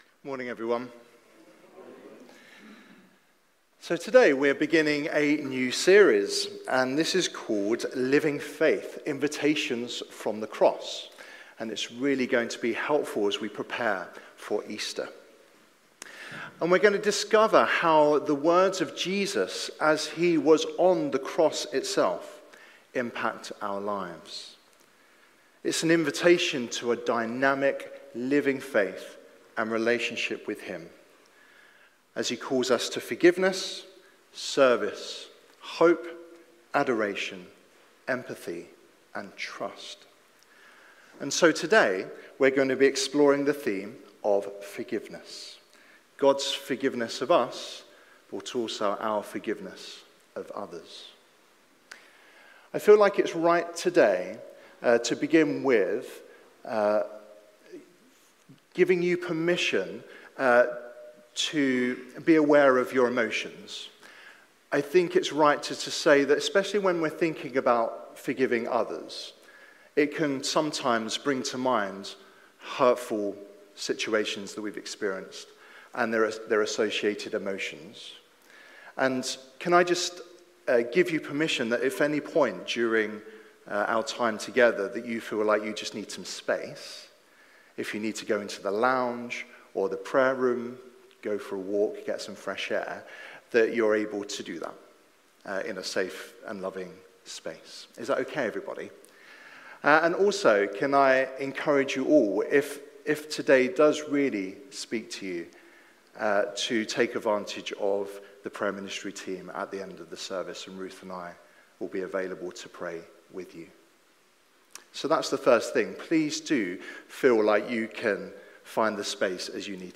Passage: Matthew 18:21-35 Service Type: Sunday Morning